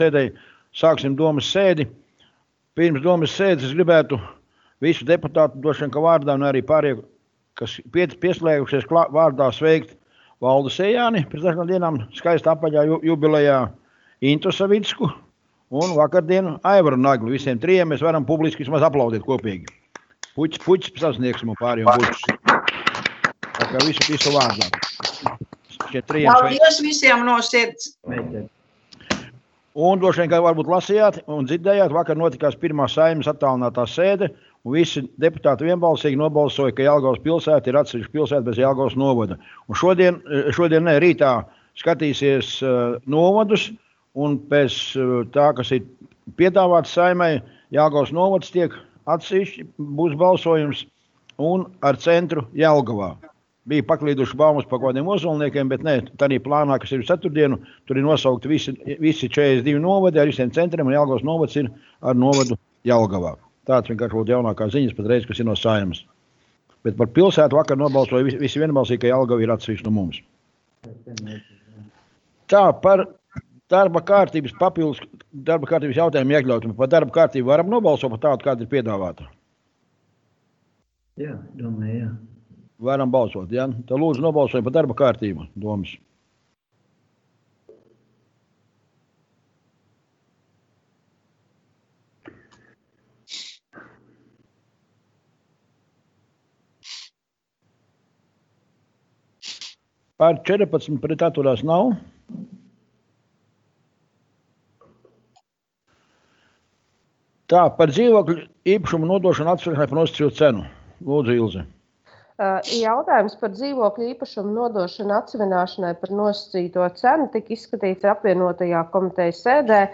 Domes sēde Nr. 11